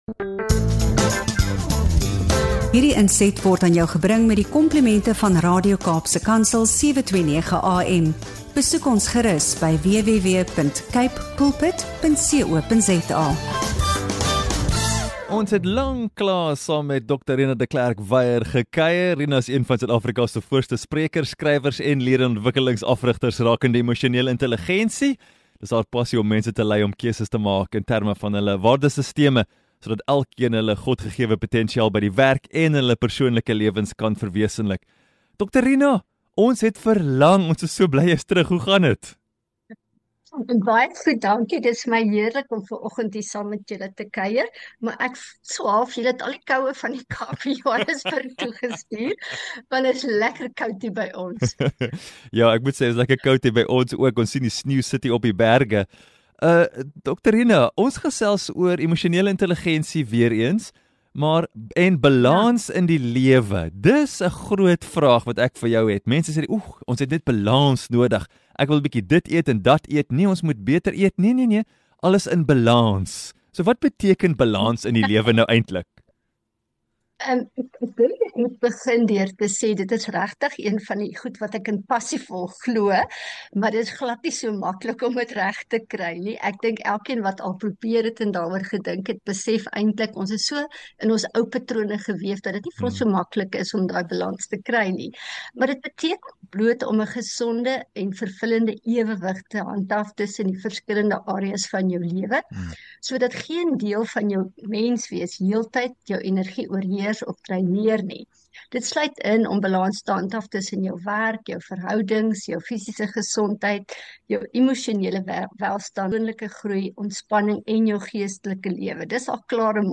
In hierdie insiggewende gesprek